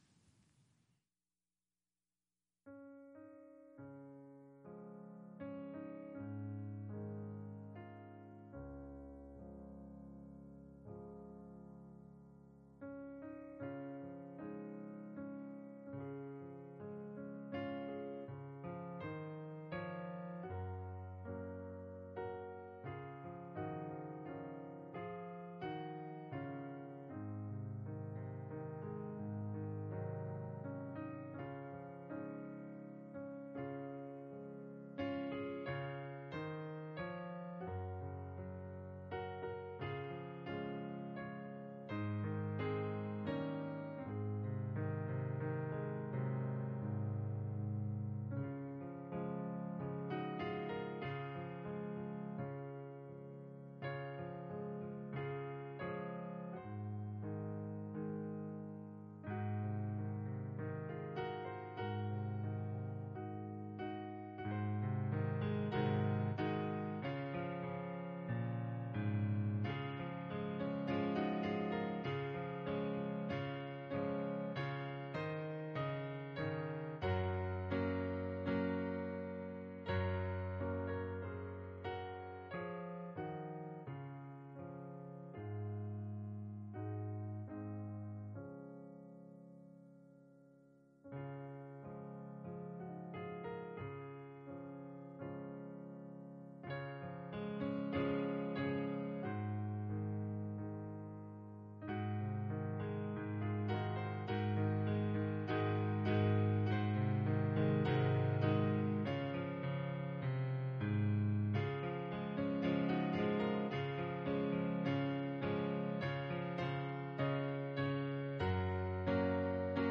An expository teaching of Romans 12:1-2. Our heart to renew our minds can be as a service to God and because of His mercies.